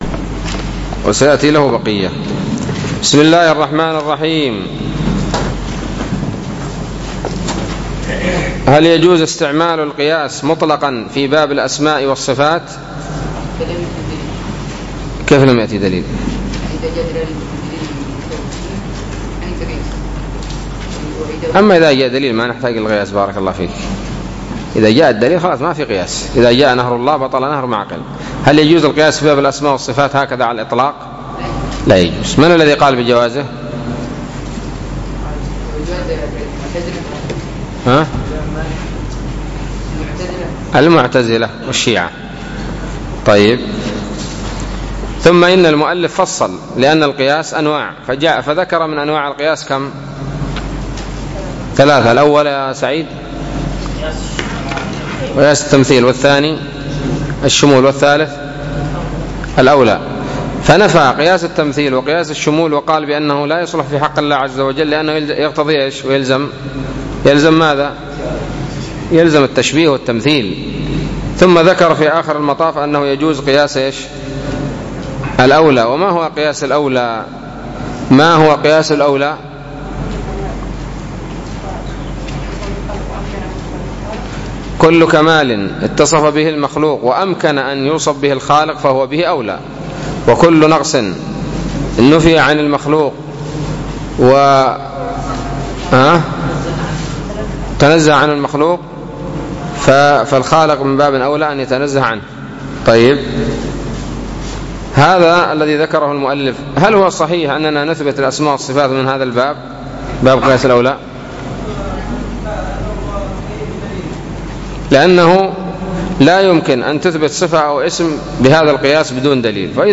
الدرس الخامس والعشرون من شرح العقيدة الواسطية